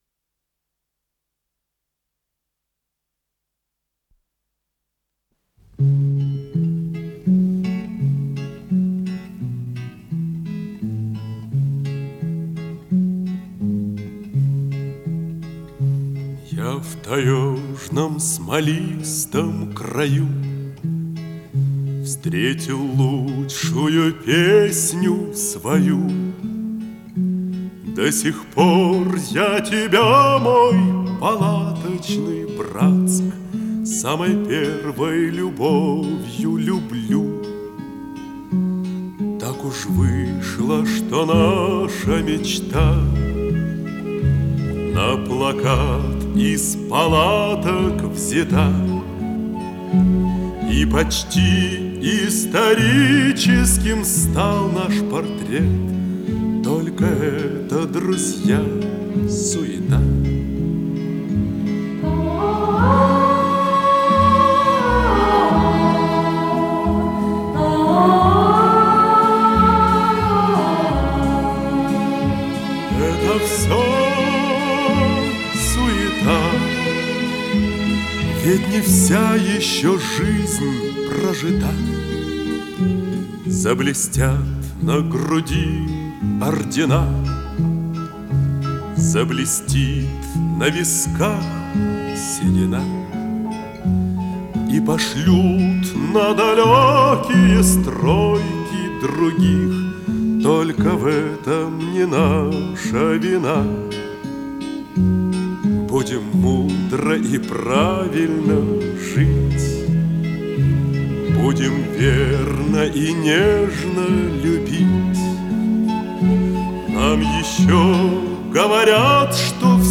с профессиональной магнитной ленты
АккомпаниментЭстрадный оркестр
Тип лентыШХЗ Тип 2